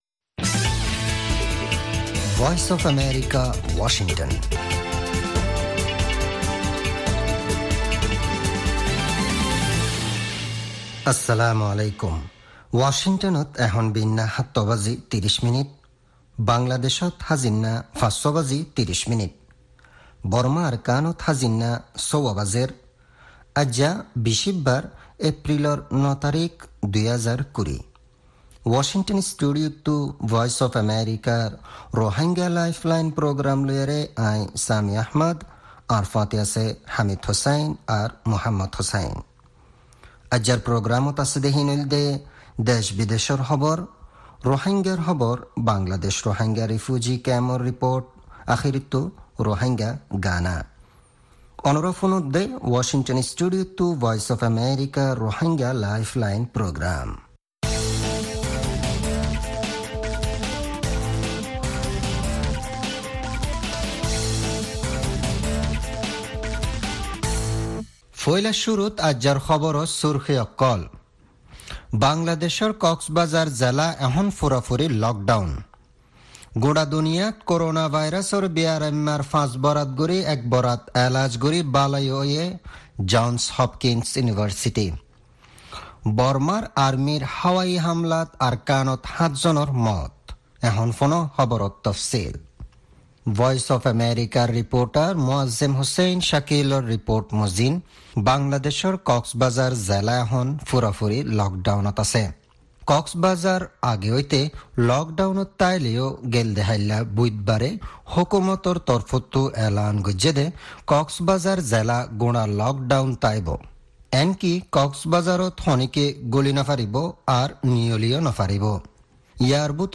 News headlines